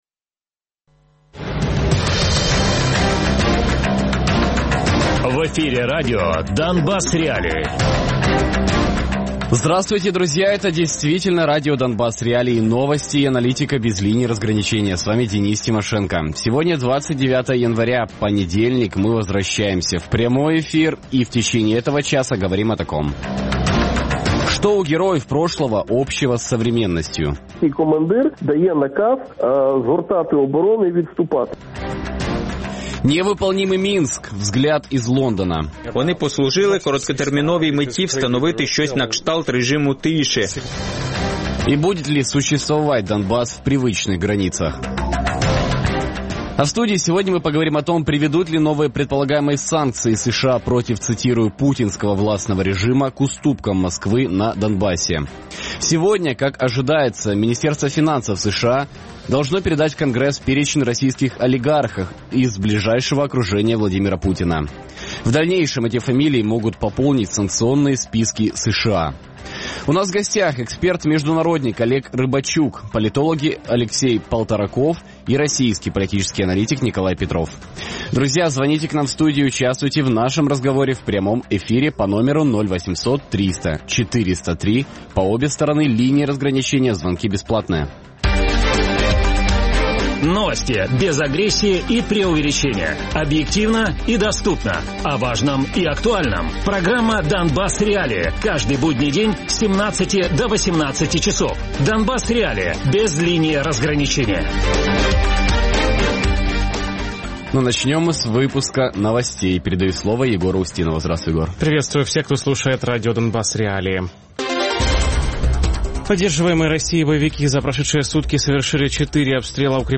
Гість: Олег Рибачук - голова громадської організації Центр UA, колишній віце-прем'єр-міністр України з питань європейської інтеграції Радіопрограма «Донбас.Реалії» - у будні з 17:00 до 18:00. Без агресії і перебільшення. 60 хвилин про найважливіше для Донецької і Луганської областей.